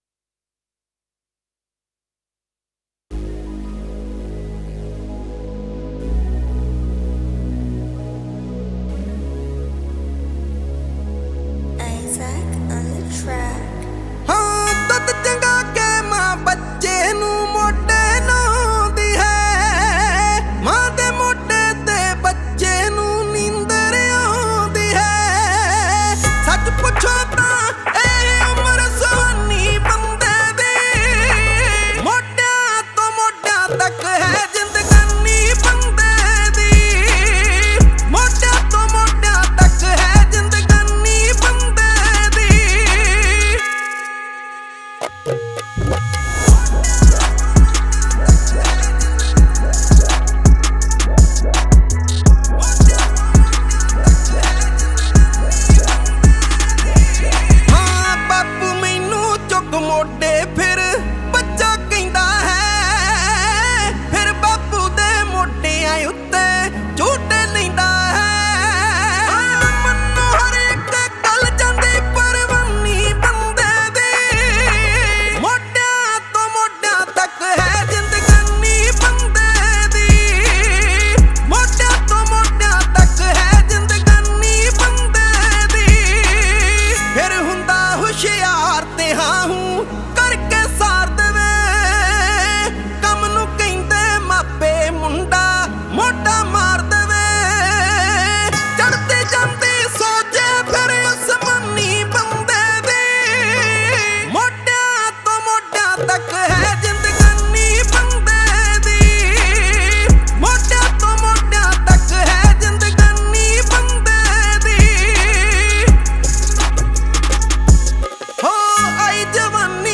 Category: Punjabi Singles